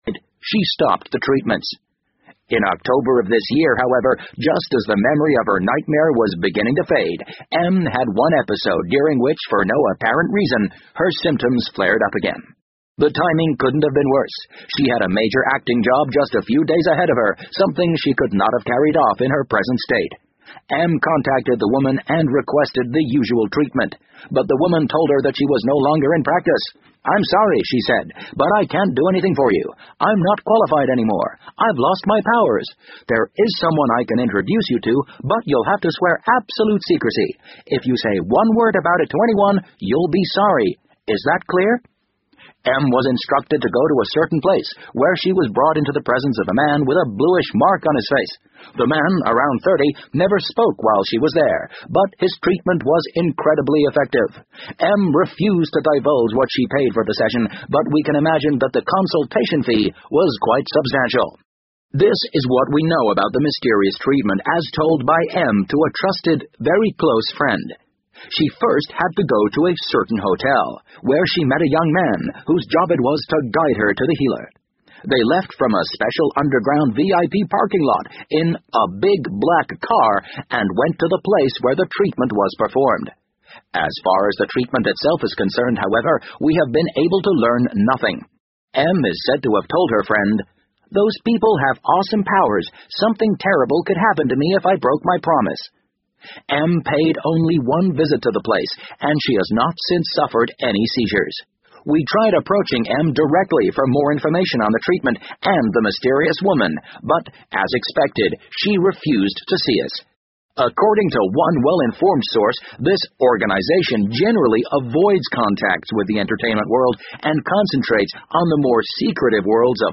BBC英文广播剧在线听 The Wind Up Bird 011 - 4 听力文件下载—在线英语听力室